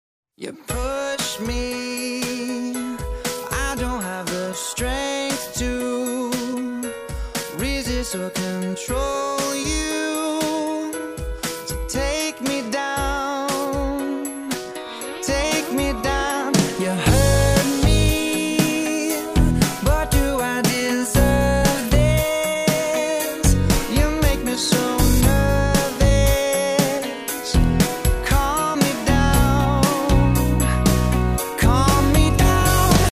• Качество: 128, Stereo
мужской вокал
soul
поп-рок
фанк-рок
синти-поп